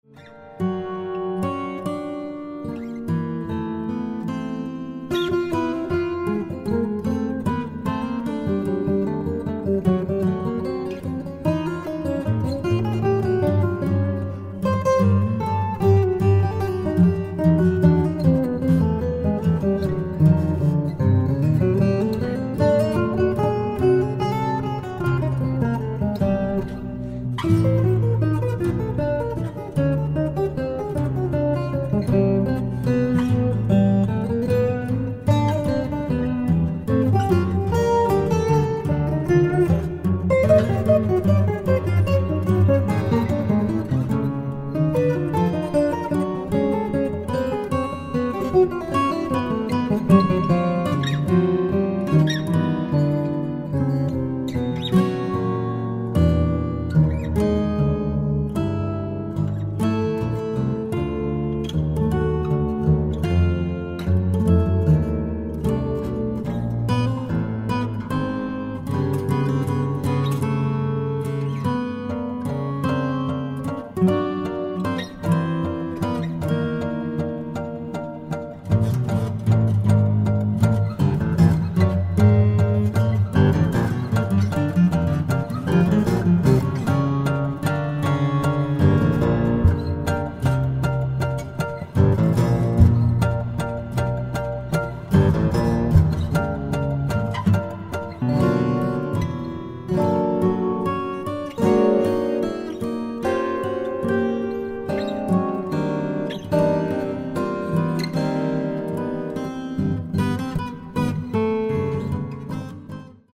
baritone guitar duets